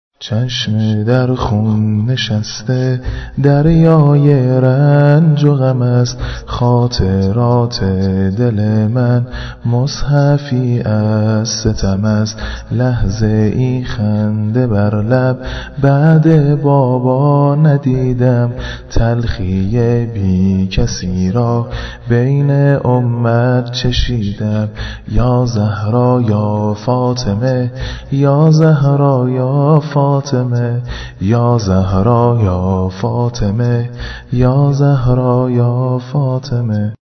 فاطمه الزهرا شهادت واحد